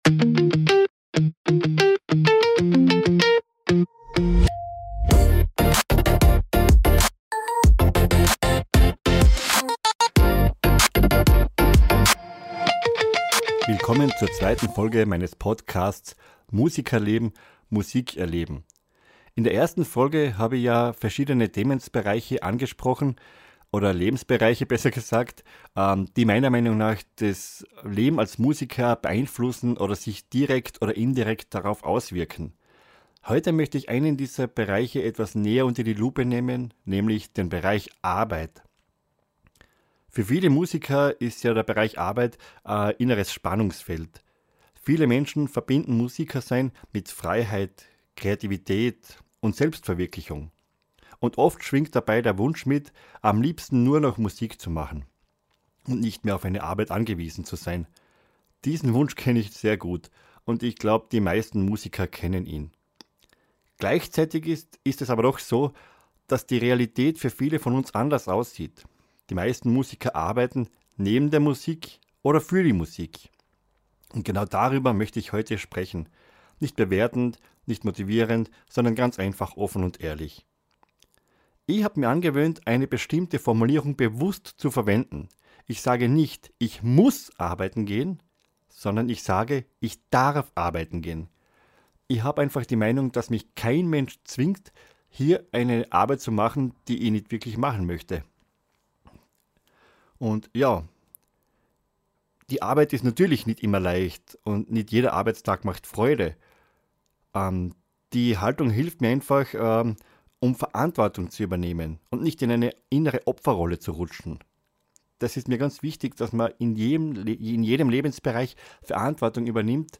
Eine ruhige, reflektierte Folge für alle, die Musik machen – und